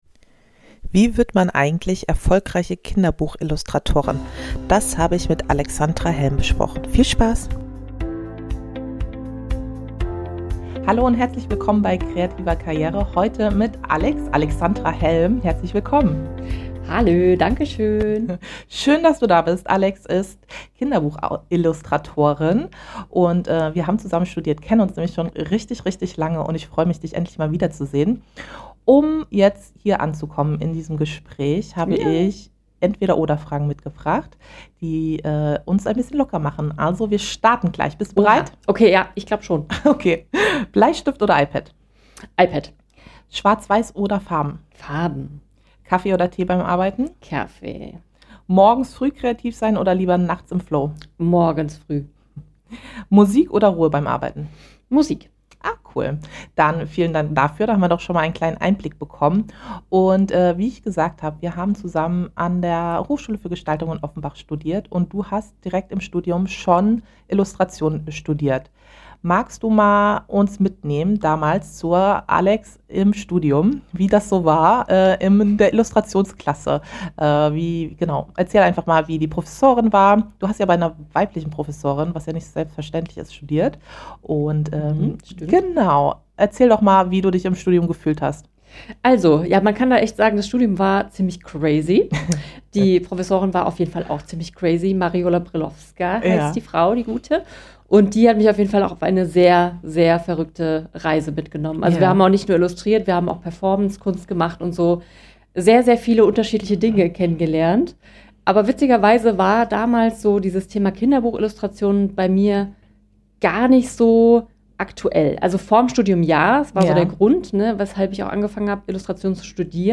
14 - Interview mit Kinderbuch Illustratorin